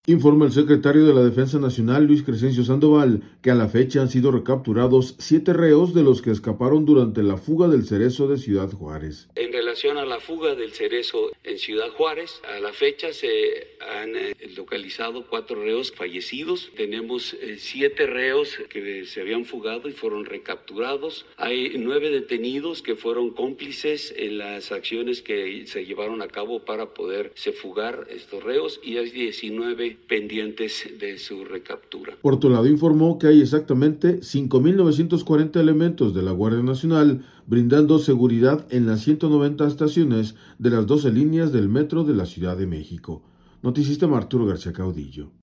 Informa el secretario de la Defensa Nacional, Luis Crescencio Sandoval, que a la fecha han sido recapturados 7 reos de los que escaparon durante la fuga del Cerezo de Ciudad Juárez.